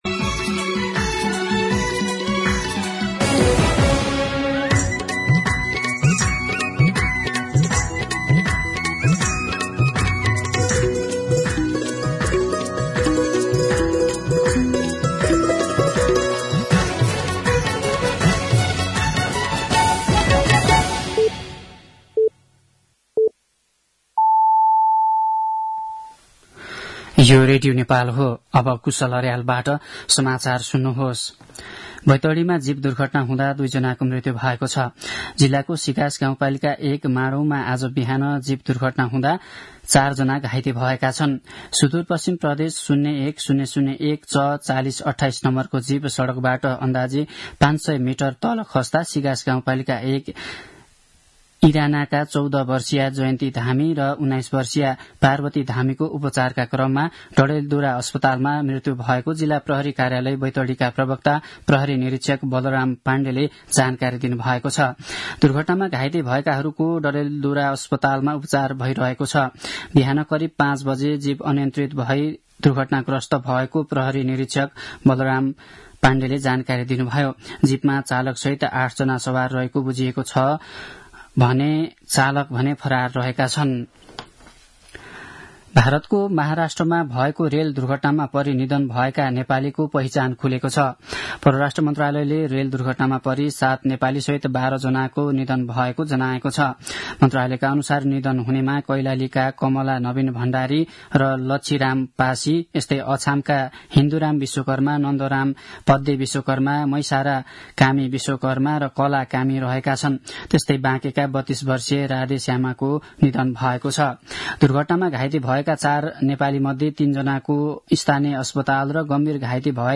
दिउँसो १ बजेको नेपाली समाचार : १३ माघ , २०८१
1pm-News-10-12.mp3